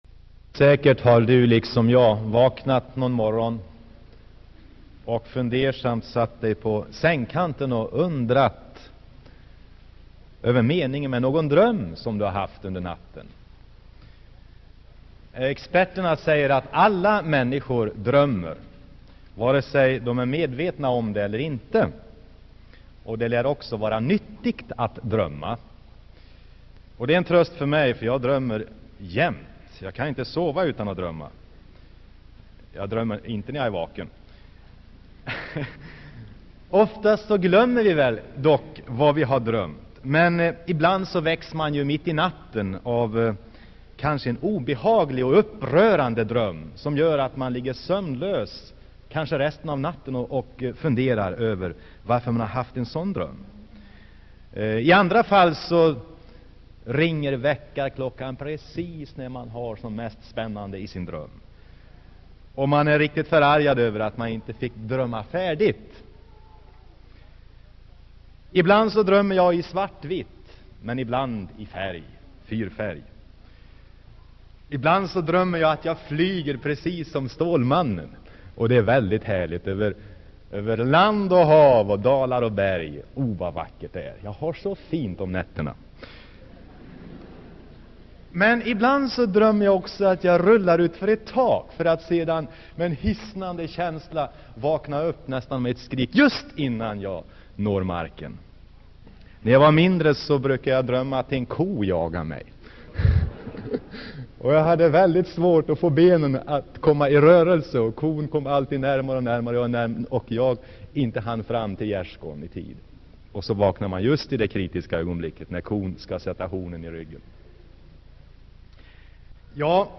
Inspelad i Saronkyrkan, Göteborg.